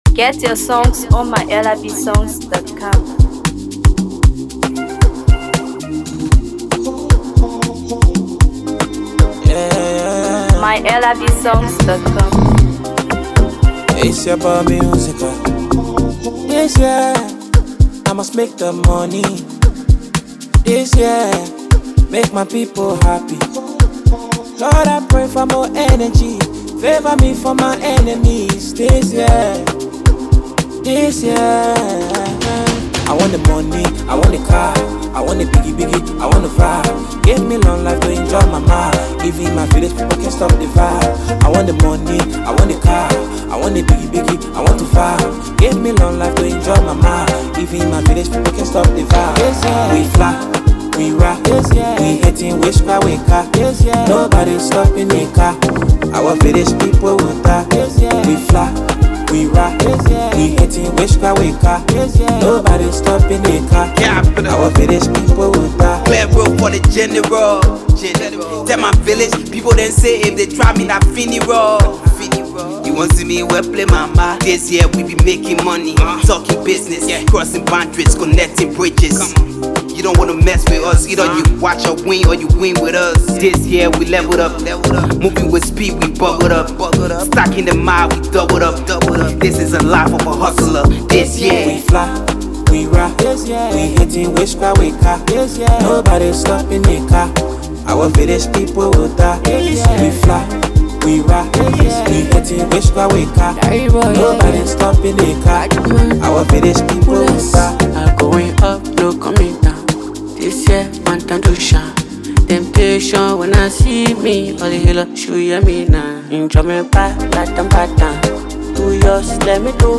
Afro PopHipcoMusic